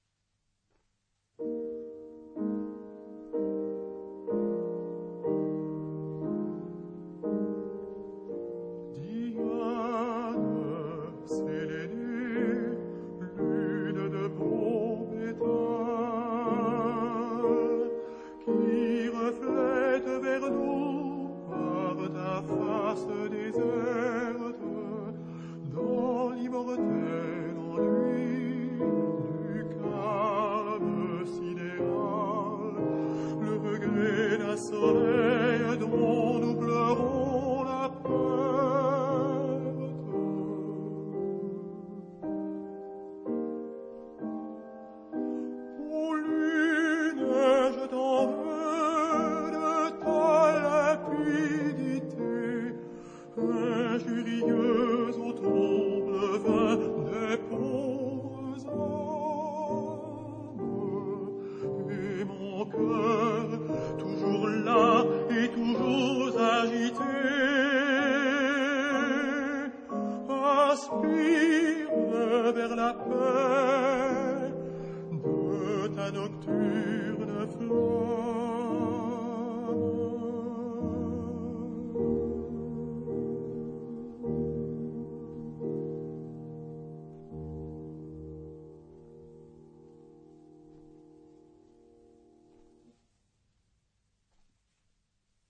他的演唱很有溫度與情感。